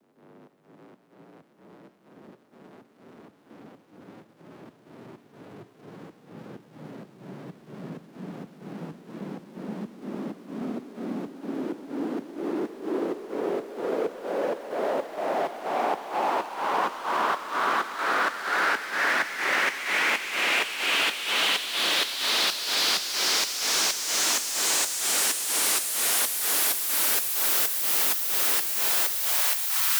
VUF1 Clean Noise Ramps 128BPM 140BPM